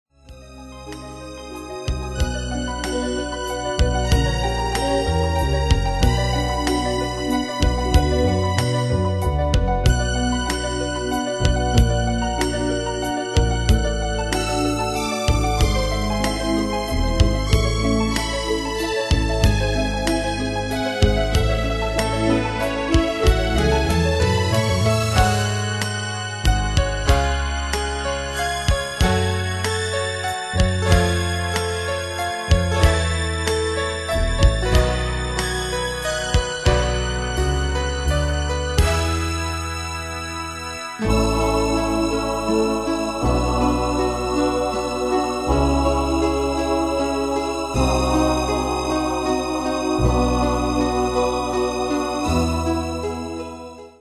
Style: PopFranco Année/Year: 1982 Tempo: 80 Durée/Time: 2.48
Danse/Dance: Ballade Cat Id.
Pro Backing Tracks